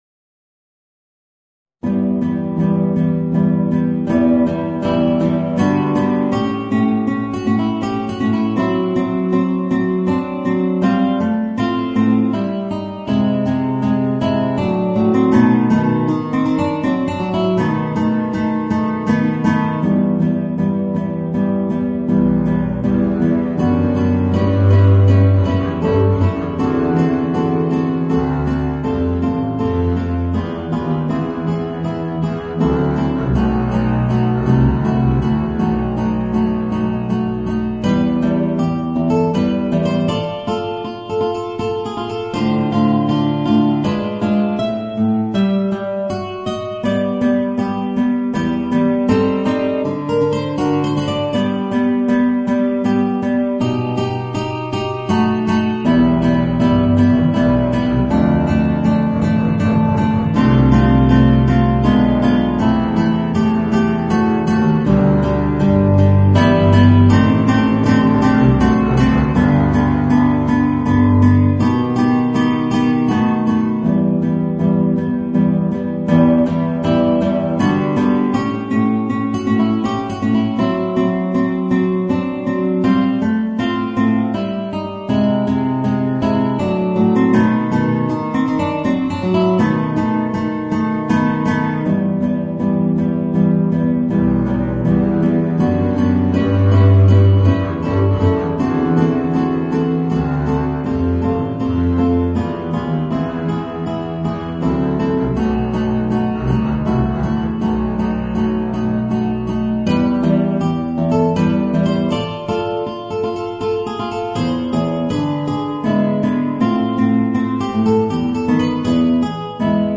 Voicing: Guitar and Contrabass